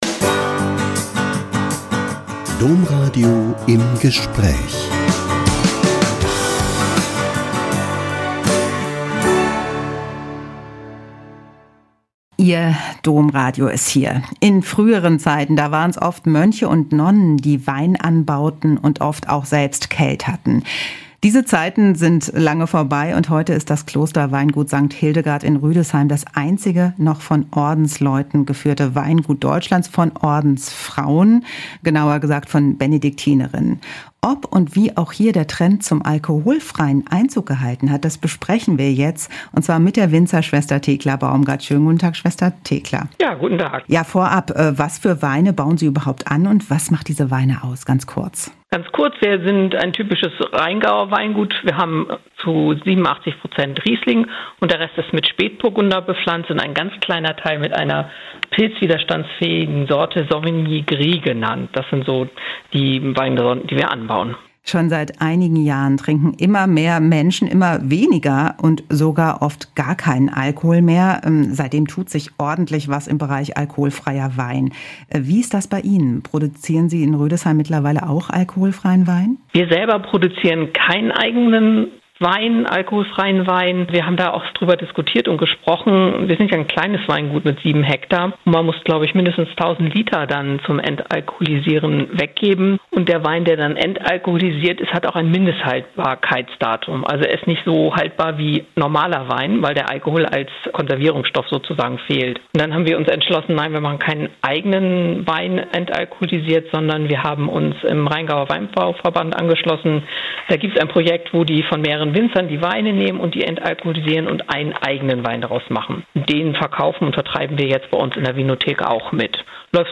Auch Bischofs- und Klosterweingüter spüren Alkoholfrei-Trend - Ein Interview